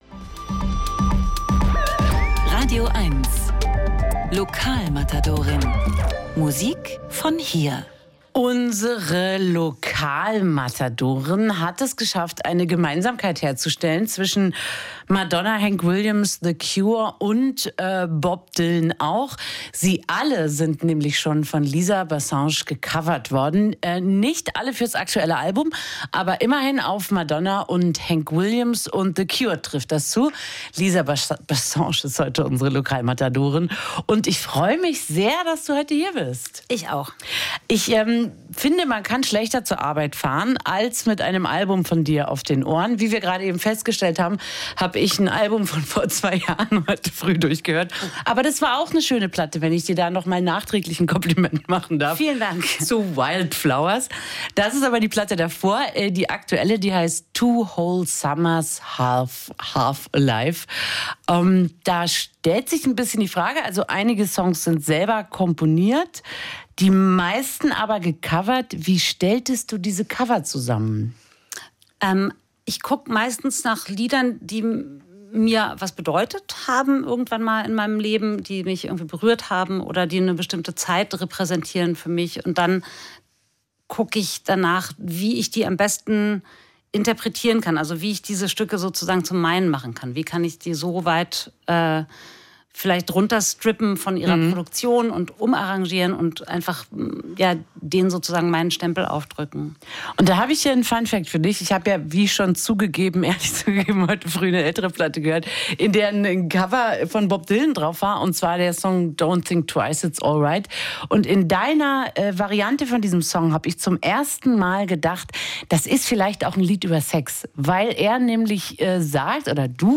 Lisa Bassenge nennt den Jazz ihre "Homebase" – doch auf ein Genre reduzieren lässt sich die Berliner Ausnahmesängerin keineswegs. Heute besucht sie uns für ein Interview und natürlich einige Höreindrücke, denn Lisa Bassenge ist diese Woche die radioeins-Lokalmatadorin.